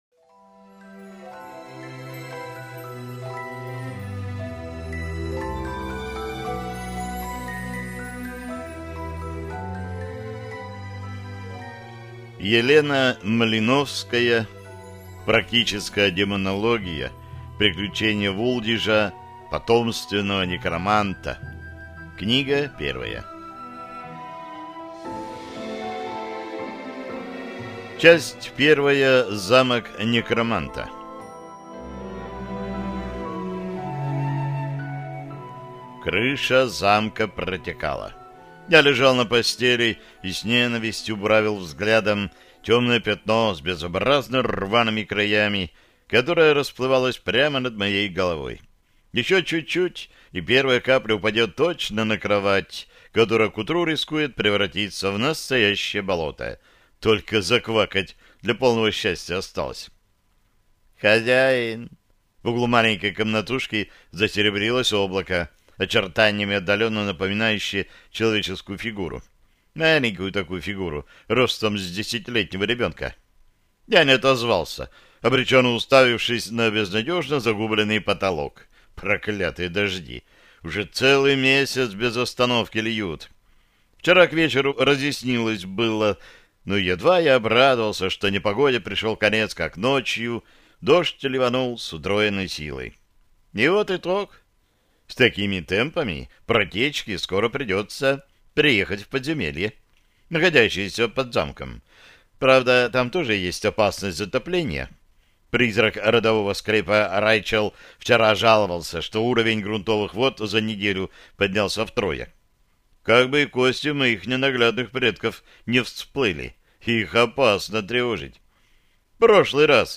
Аудиокнига Практическая демонология | Библиотека аудиокниг